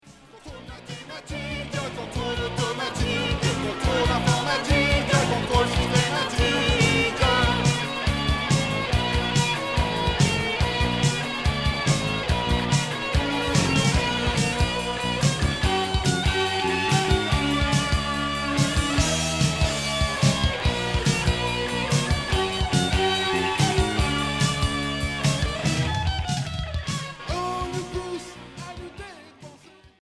Hard progressif Unique 45t retour à l'accueil